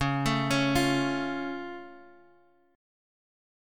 C#+ chord